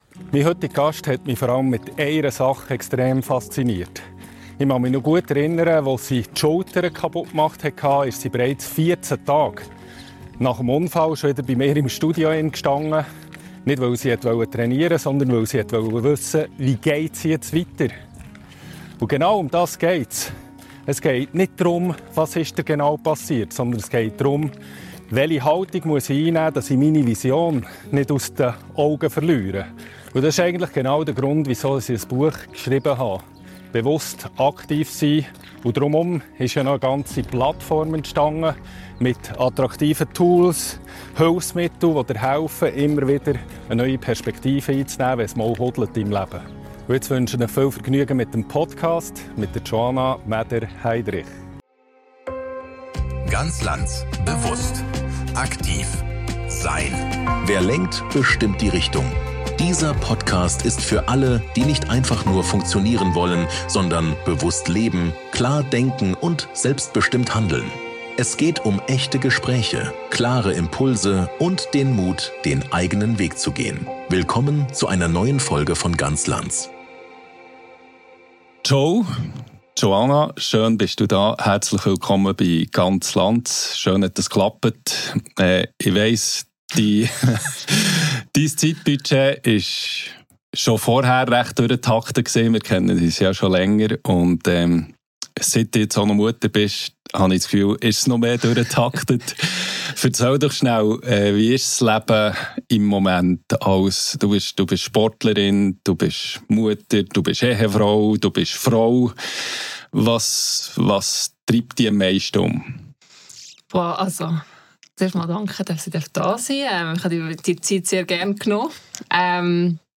Ein Gespräch über Leistung, Prioritäten, Selbstführung und den Mut, nach Rückschlägen wieder neu anzutreten.